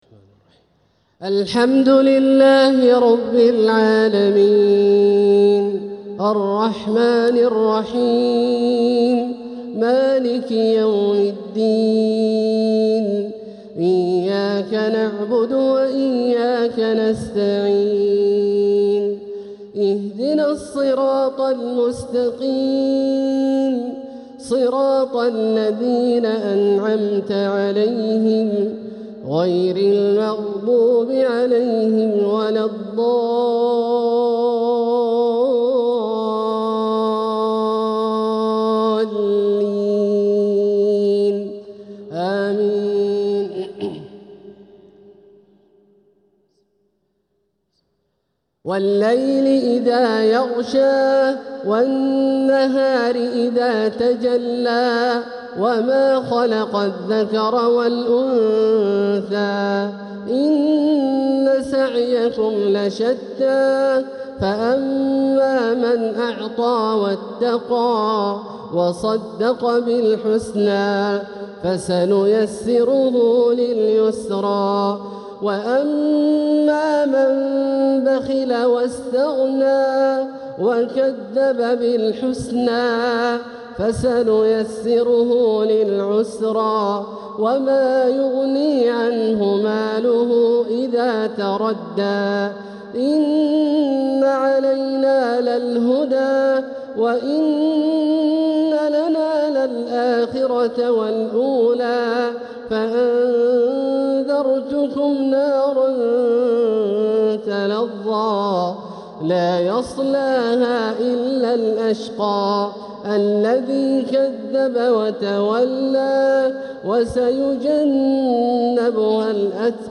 تلاوة لسورتي الليل و الضحى | عشاء الخميس 2-9-1447هـ > ١٤٤٧هـ > الفروض - تلاوات عبدالله الجهني